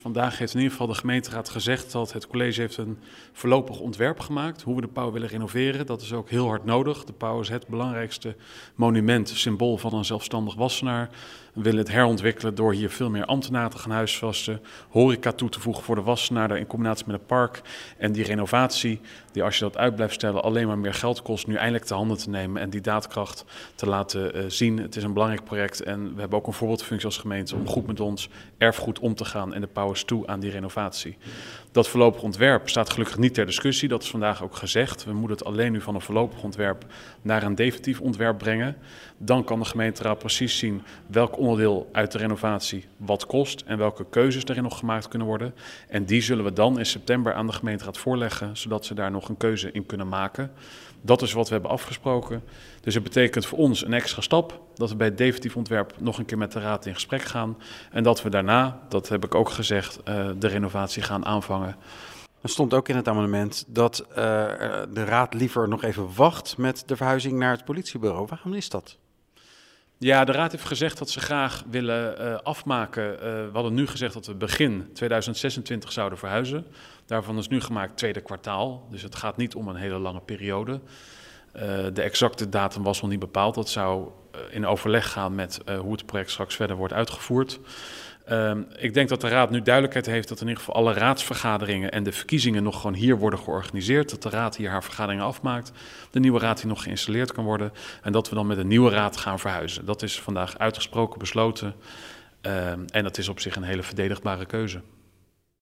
gaat in gesprek met wethouder Wethouder Laurens van Doeveren over de keuzes van de raad